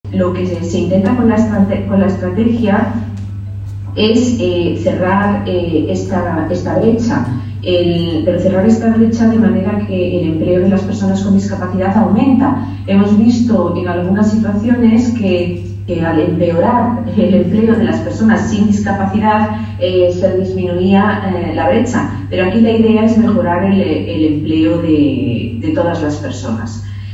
Embajadores, cónsules y consejeros de embajadas participaron en la sede ‘Por Talento Digital’, de Fundación ONCE, en el VII Encuentro Diplomacia para la Inclusión organizado por el Grupo Social ONCE y la Academia de la Diplomacia, bajo el patrocinio del embajador de la República Checa en nuestro país, que ostenta la presidencia semestral del Consejo de la UE.